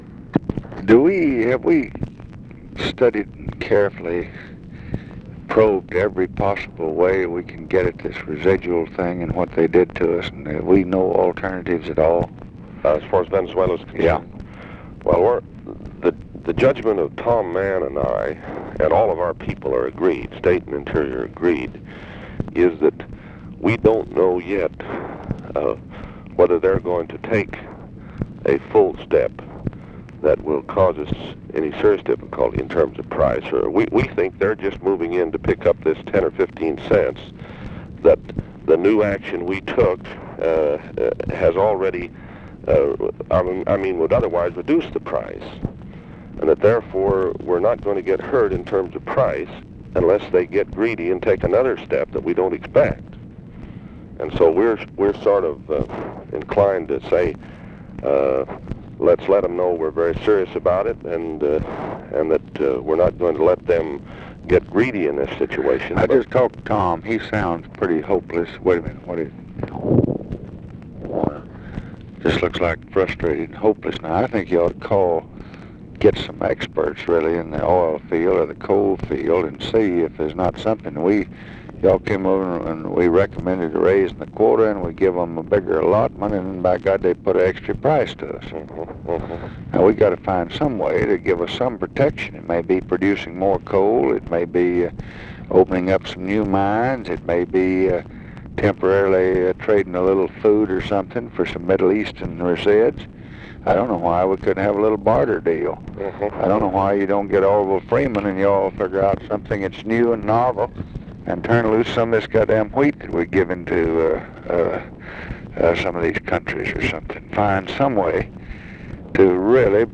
Conversation with STEWART UDALL, January 14, 1966
Secret White House Tapes